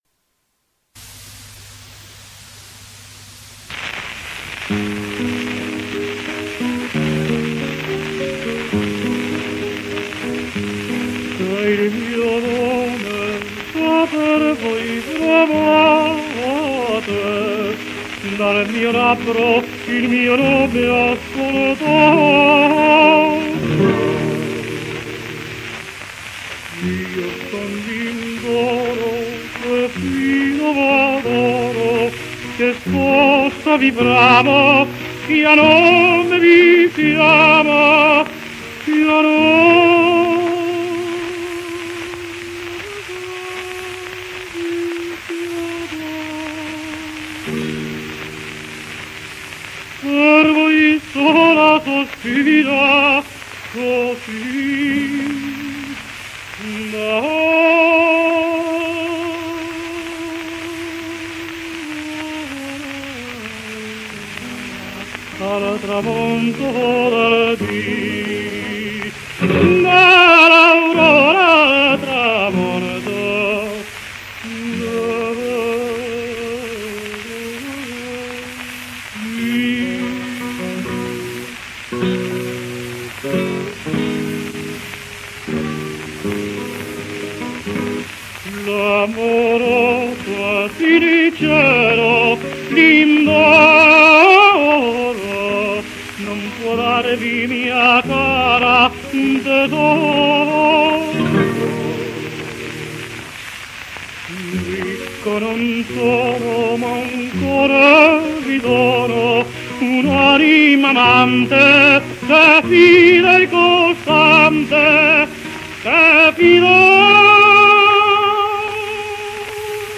with guitar played by the tenor